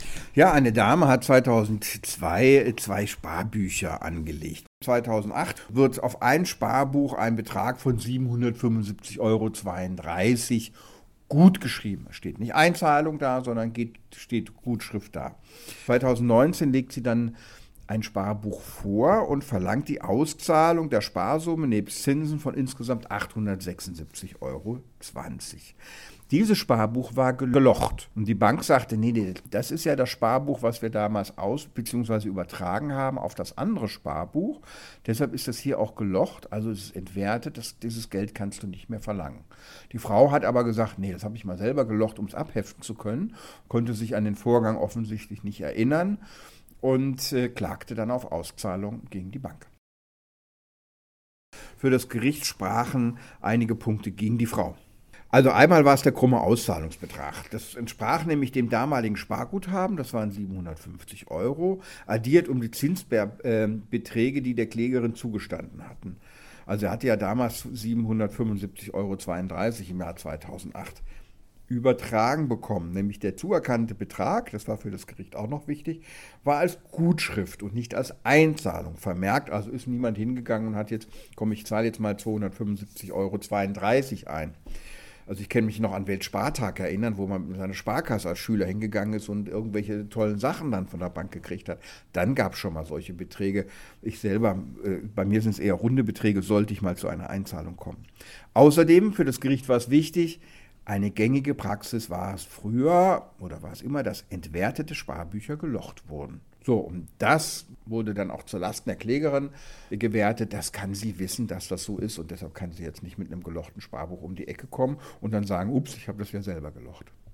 Kollegengespräch: Sparbuch gelocht – und trotzdem Kohle von der Bank?